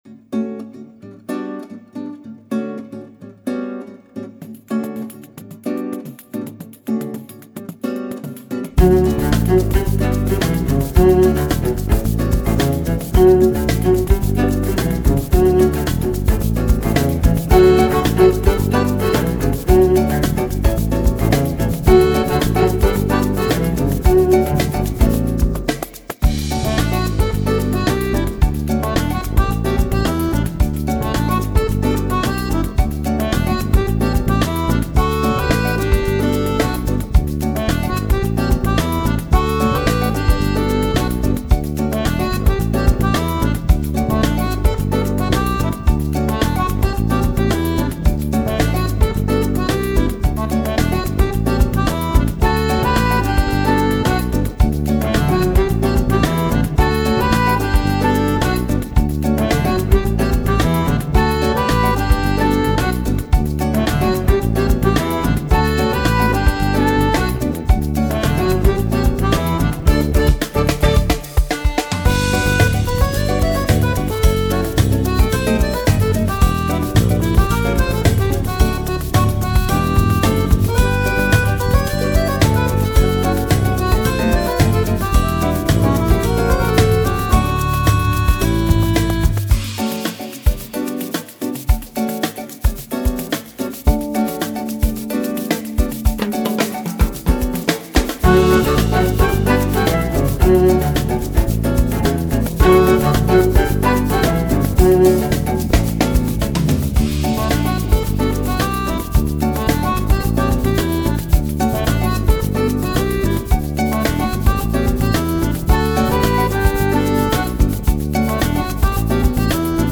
Стиль - MPB (Musica Popular Brasileira)